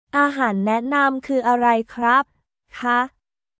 アーハーン ナエナム クー アライ クラップ／カ